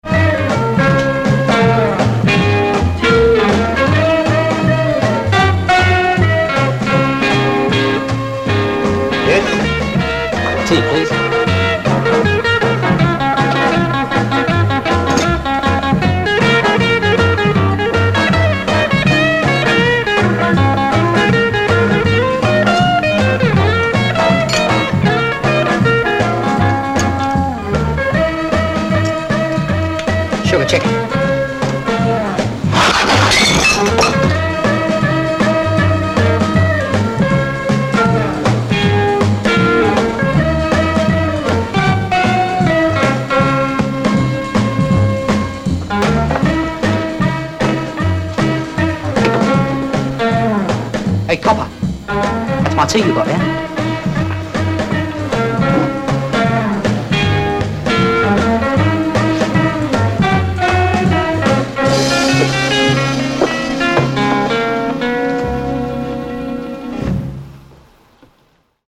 Кстати, шумы фильма из композиций я не стал вырезать.